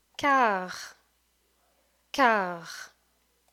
Note: the u and t of  ‘quart‘ are both silent!